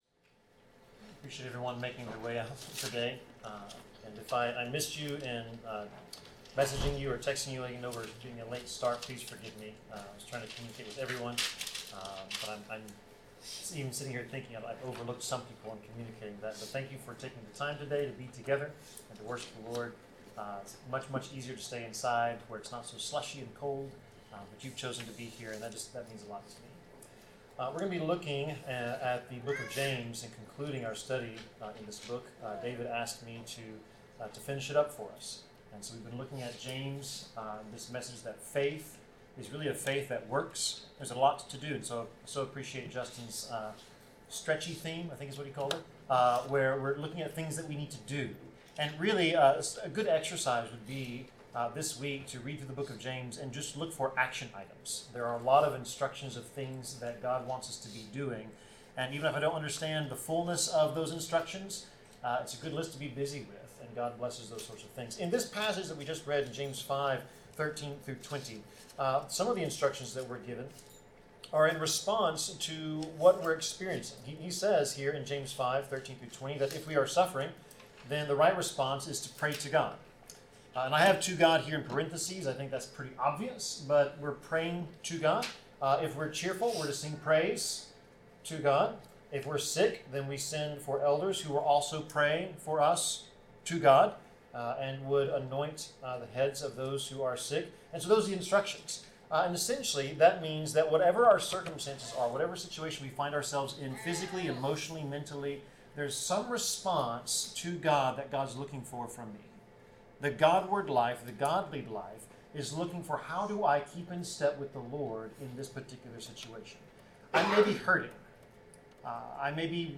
Passage: James 5:13-20 Service Type: Sermon